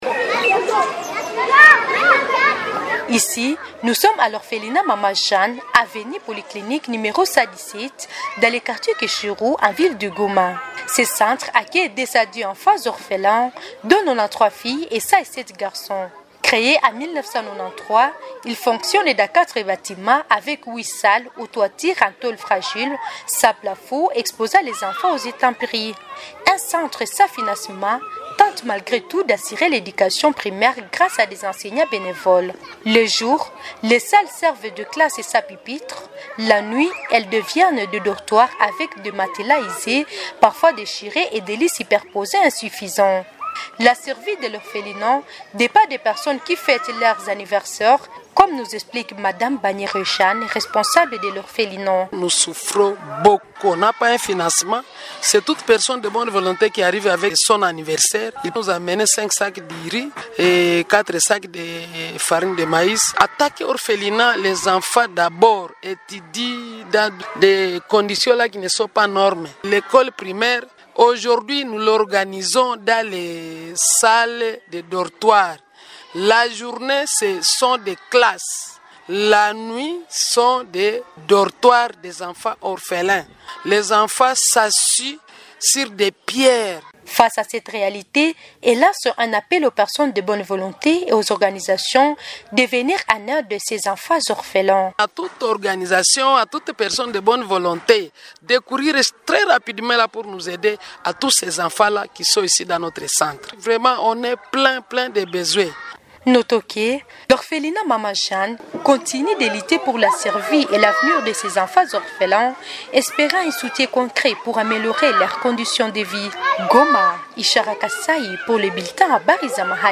FR-REP-ORPHELINAT-GOMA.mp3